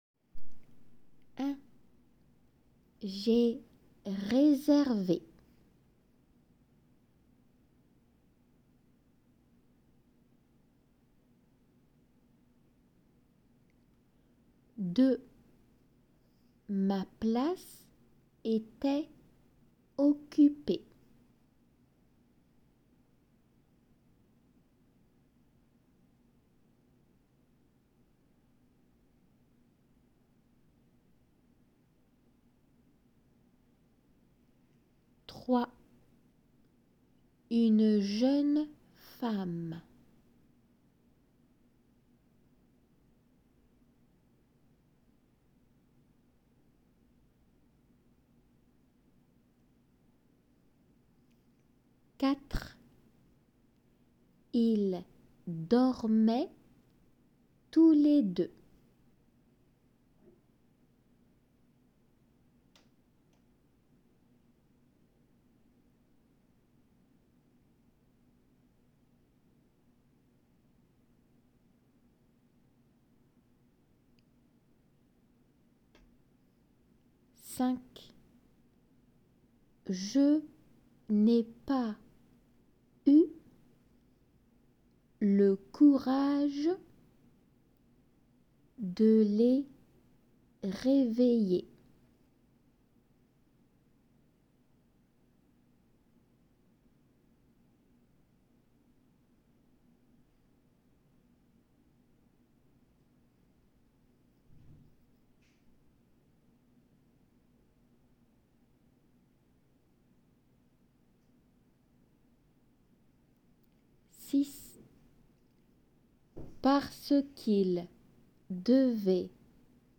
このホームページでは録音者の都合で実際の試験場での　読み手に則さずの録音になって